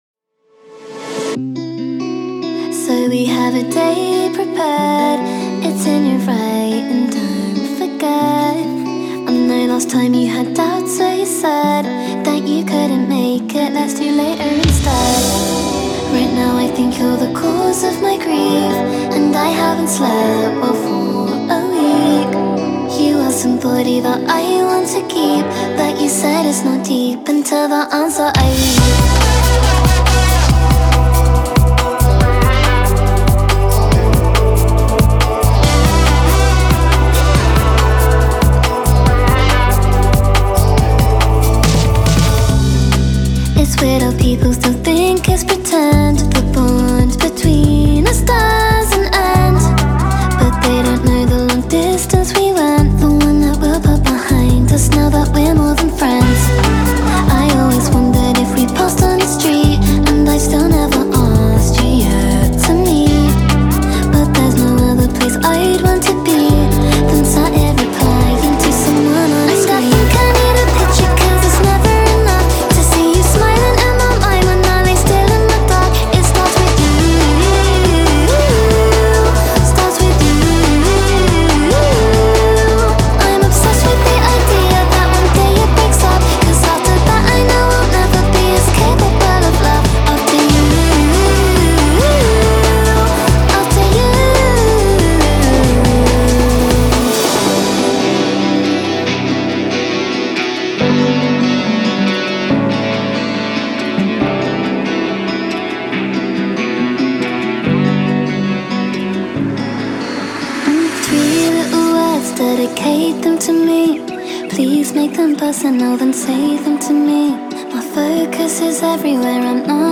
Talented female singer and record producer